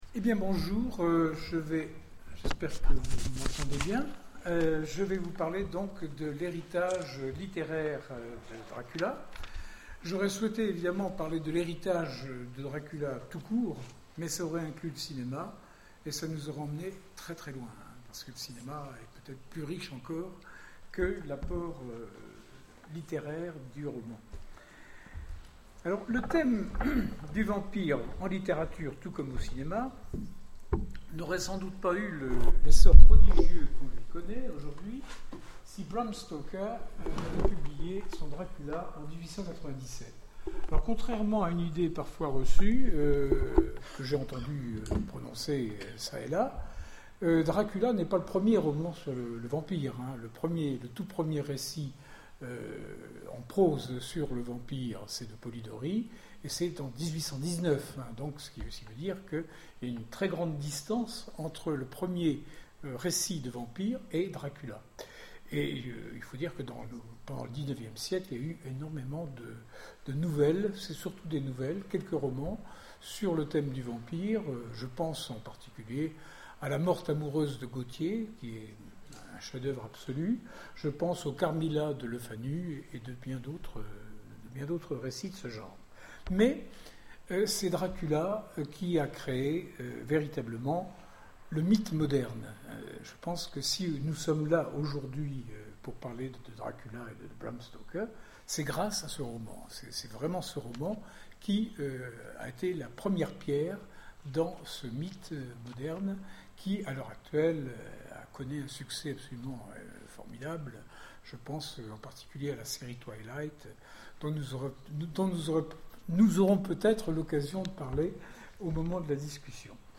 Salon du Vampires 2012 : L’héritage littéraire de Dracula
Conférence